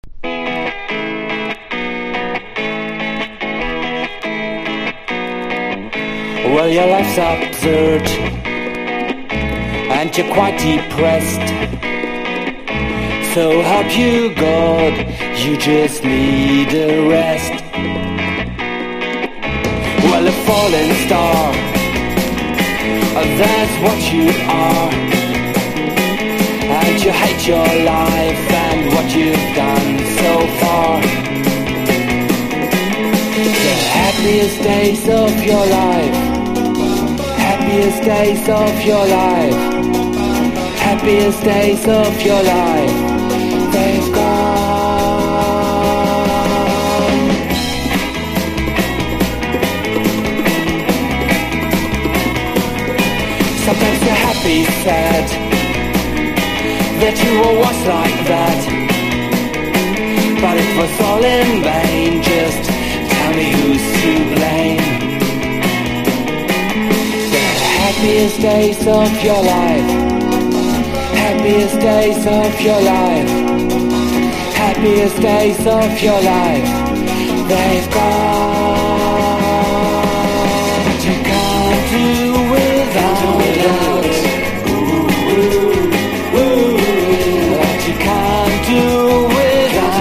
NEO ACOUSTIC / GUITAR POP
ピアノ、ホーン、キラキラなギターのリフと言うことなしな一枚。